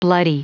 Prononciation du mot bloody en anglais (fichier audio)
Vous êtes ici : Cours d'anglais > Outils | Audio/Vidéo > Lire un mot à haute voix > Lire le mot bloody